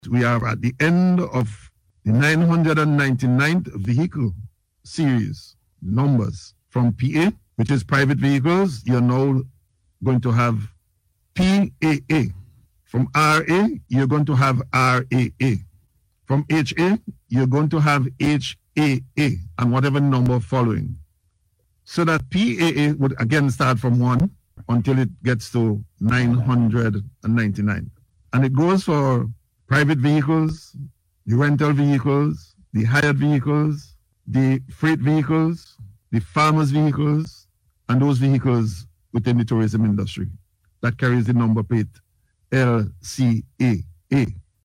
Minister of Transport, Montgomery Daniel, made the announcement on NBC Radio on Wednesday